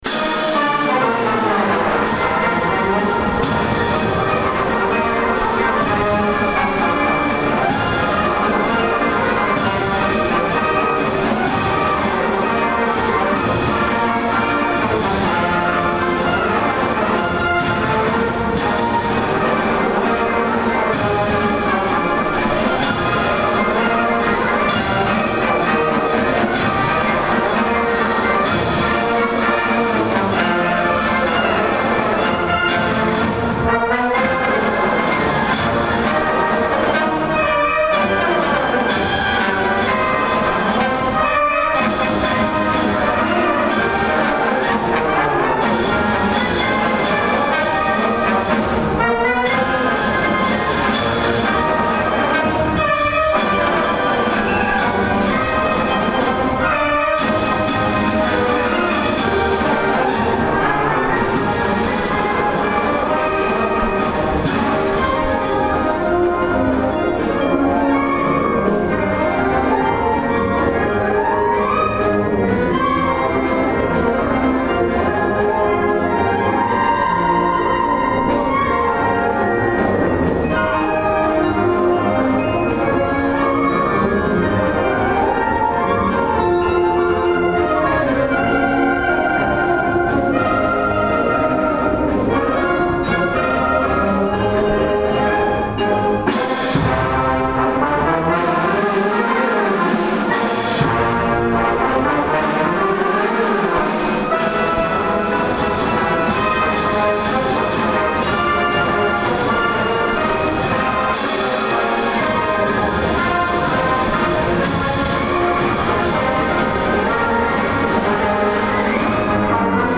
The Karl L. King Municipal Band of Fort Dodge, Iowa
the performance to be tranferred to Decker Auditorium at Iowa Central Community College.
march